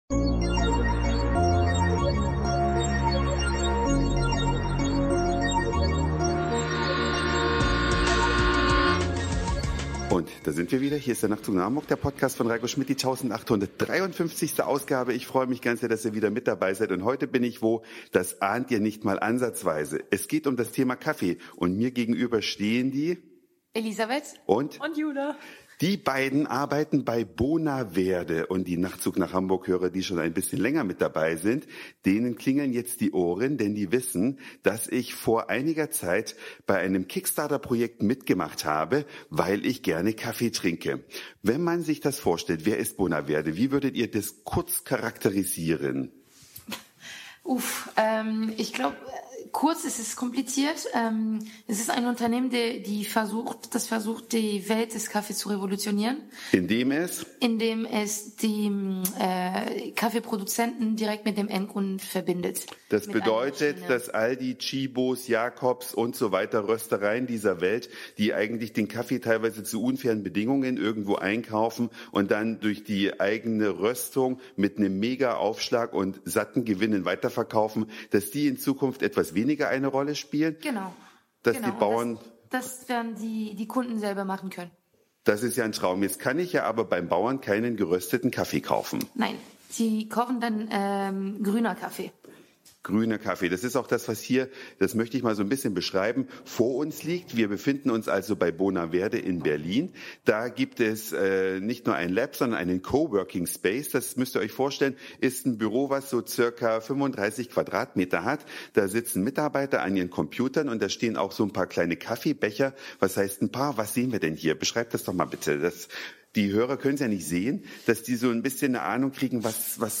Zu Gast bei Bonaverde in Berlin, den Revolutionären des Kaffeekonsums.
Die kleinen Störgeräusche und Lautstärkeprobleme bitte ich freundlich zu übersehen beziehungsweise zu überhören ;-) Mehr